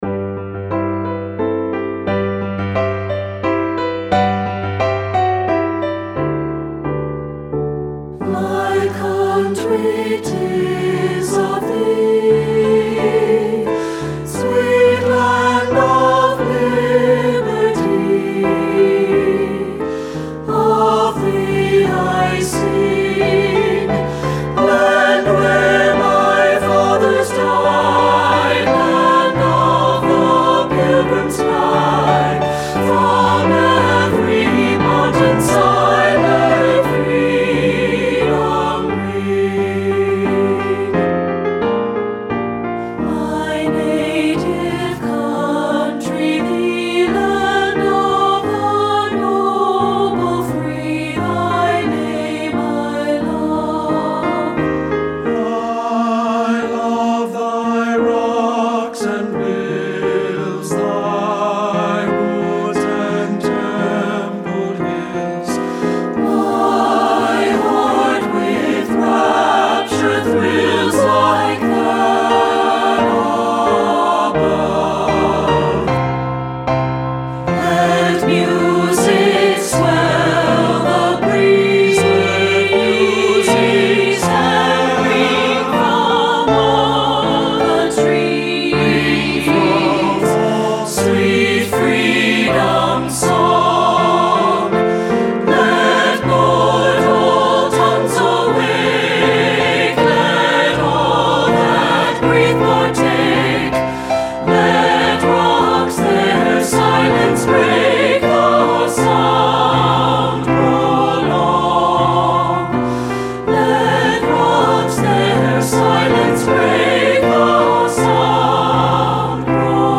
Studio Recording
accessible three-part mixed voice setting
Ensemble: Three-part Mixed Chorus
Key: G major
Accompanied: Accompanied Chorus